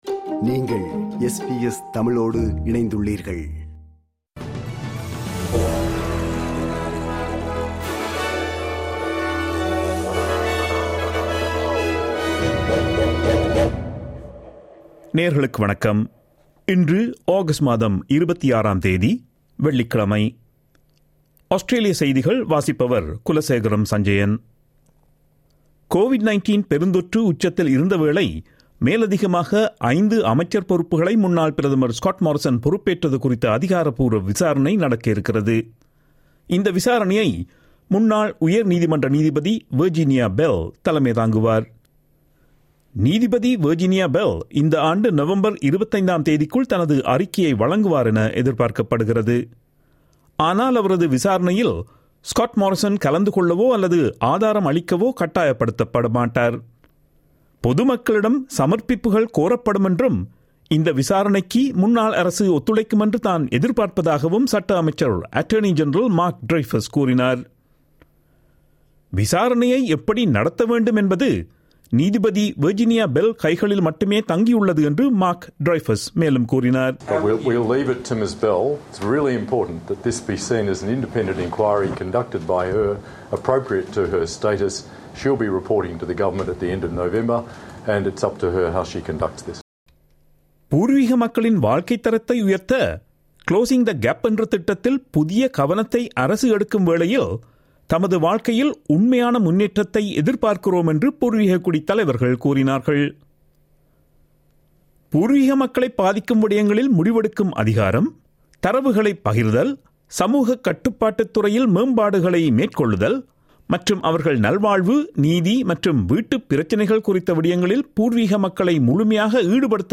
SBS தமிழ் ஒலிபரப்பின் இன்றைய (வெள்ளிக்கிழமை 26/08/2022) ஆஸ்திரேலியா குறித்த செய்திகள்.